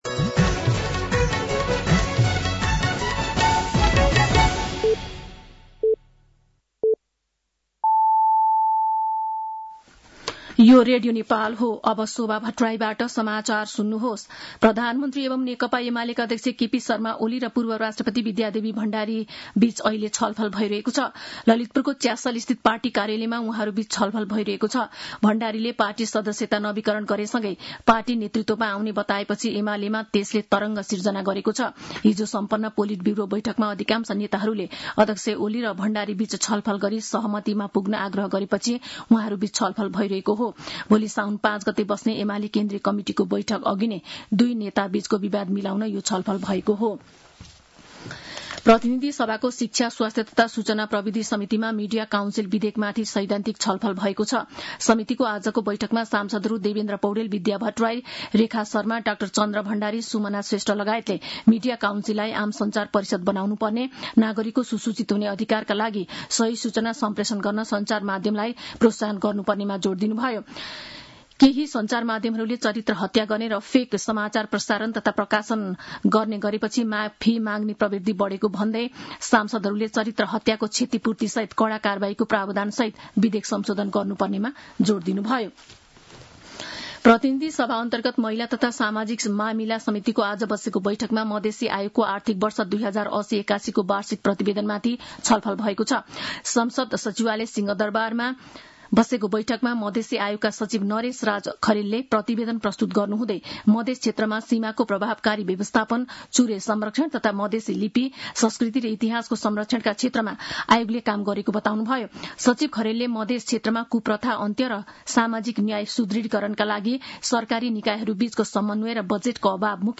साँझ ५ बजेको नेपाली समाचार : ४ साउन , २०८२
5pm-nepali-news-4-4.mp3